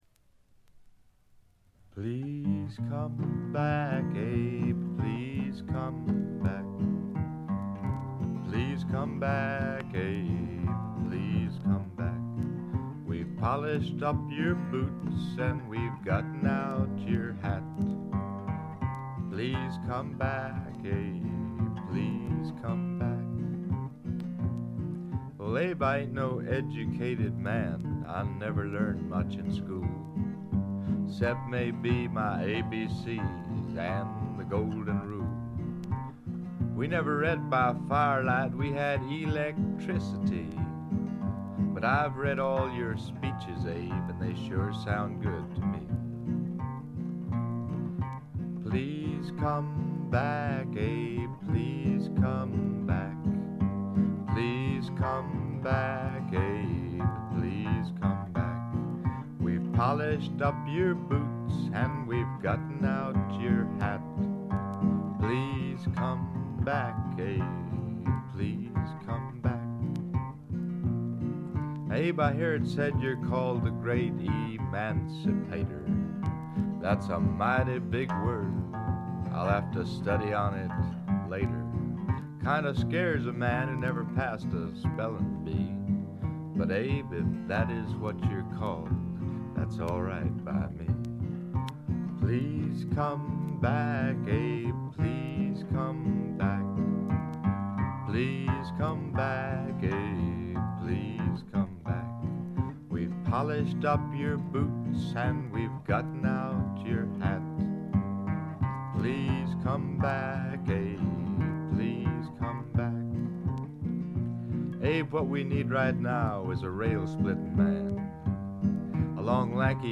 プレス起因と思われますが、ところどころでチリプチ。散発的なプツ音少し。
ジャケットからして異様な存在感を放っていますが、音の方も独特のノリがあって一種呪術的なすごい迫力です！
試聴曲は現品からの取り込み音源です。
Guitar, Vocals
Indian Drums